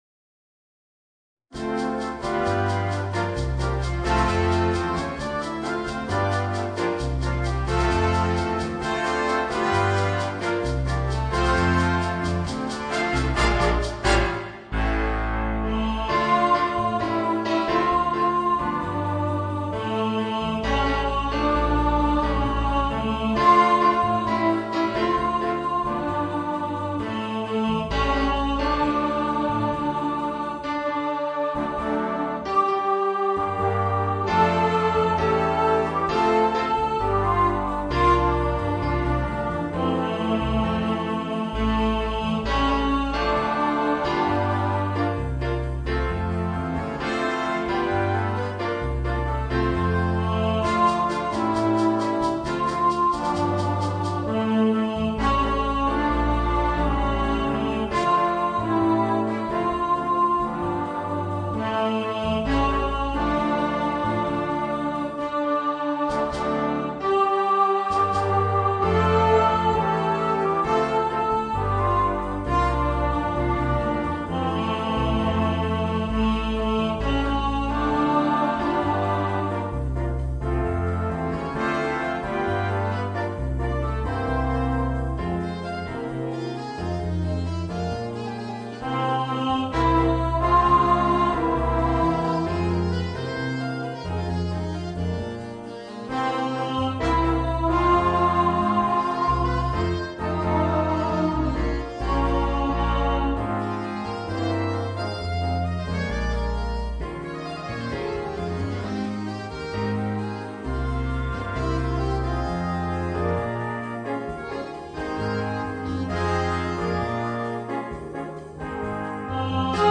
Orchestre à Vent